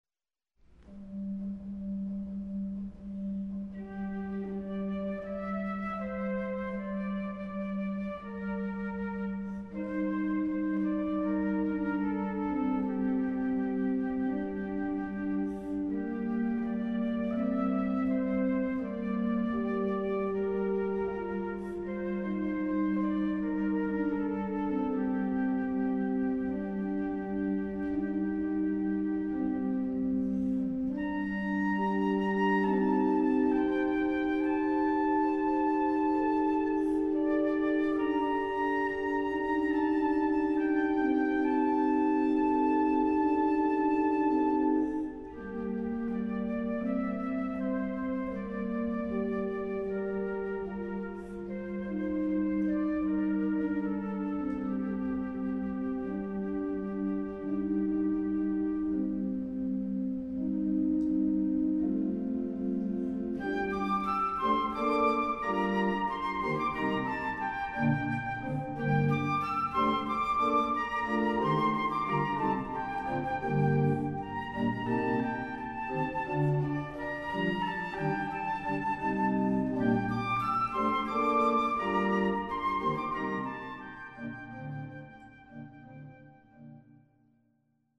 Voicing: Flute and Organ